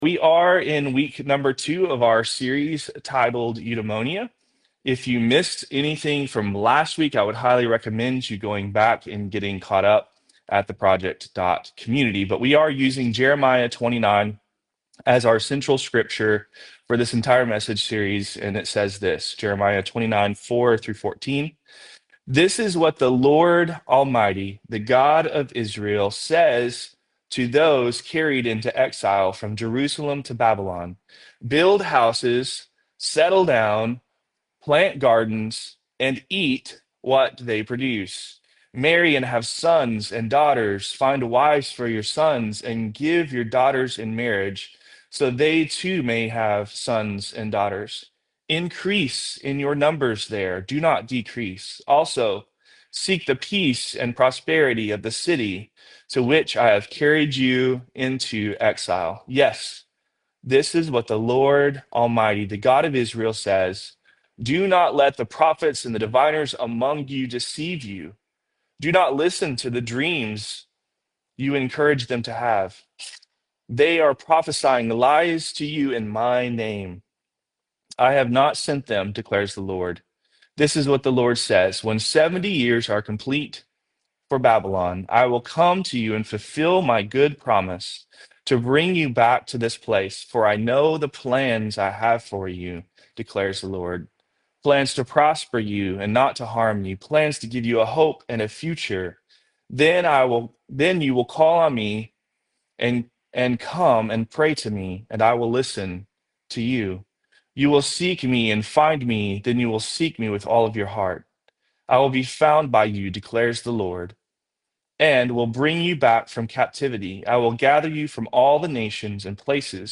Sermons | Project Community